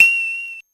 Sound effect for when the player changes selection in menus.